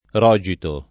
r0Jito] s. m. (giur. «atto notarile») — es. con acc. scr.: distolto da rògito o caparra [diSt0lto da rr0Jito o kkap#rra] (D’Annunzio) — dal ’300 al ’700, talvolta nella forma rogo [r0go], quasi solo sing. (raro il pl. roghi) e più spesso premessa al nome del rogatario (per il rogo di ser…)